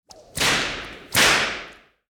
Play, download and share Whip BB original sound button!!!!
whip-bb.mp3